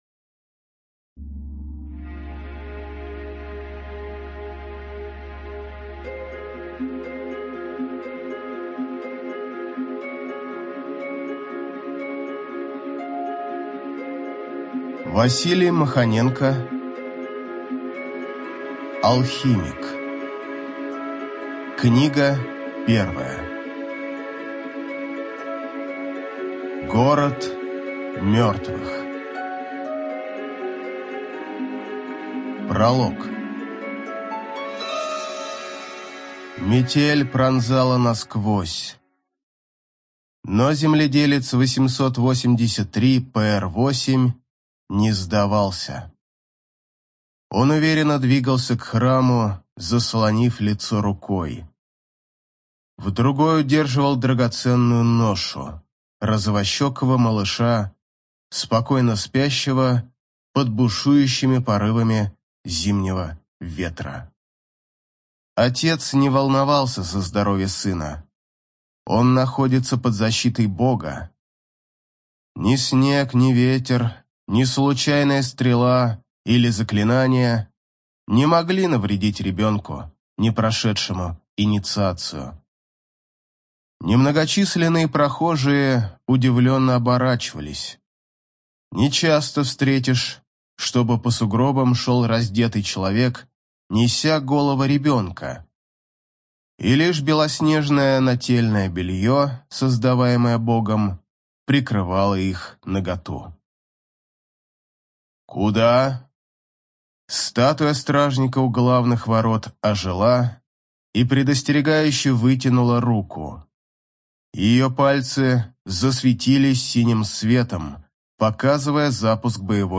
ЖанрБоевики, Фэнтези